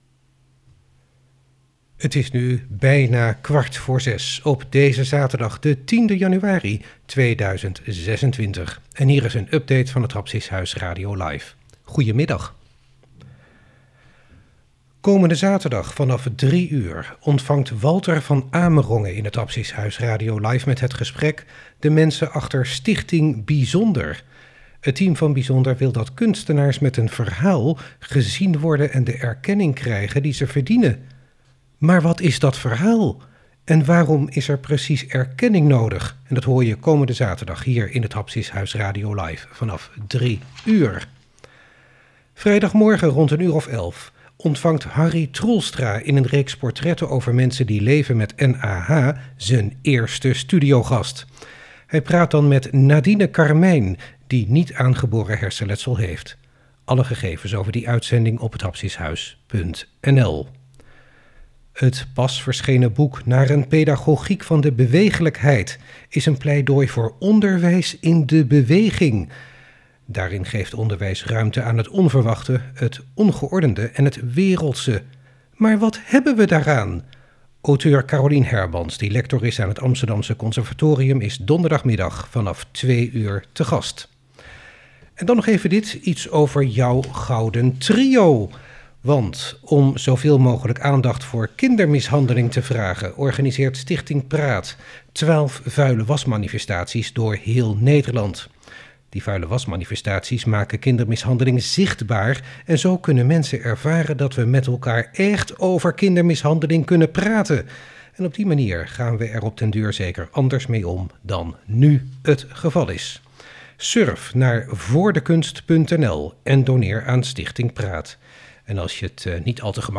🎧 Update van Het Hapsis Huis radio LIVE | Informatie over aankomende programma’s | zaterdag 10 januari 2026 | 17:44 - ± 17:47 |